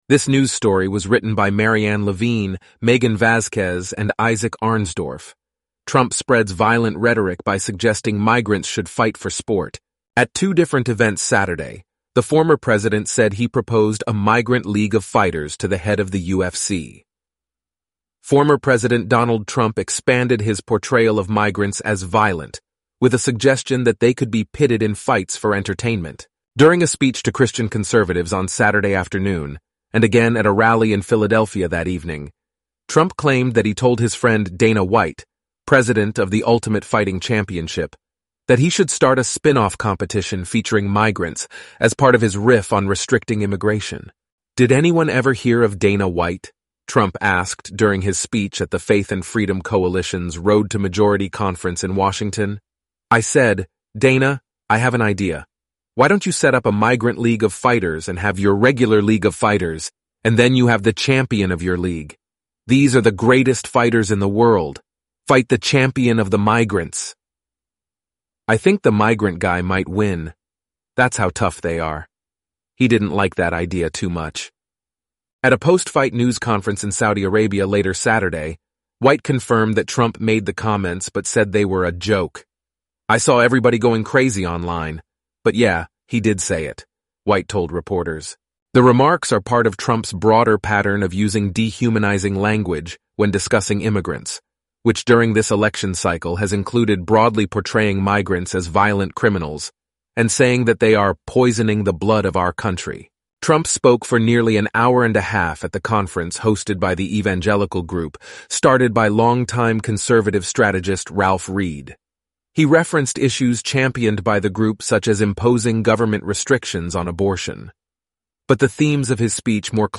eleven-labs_en-US_Antoni_standard_audio.mp3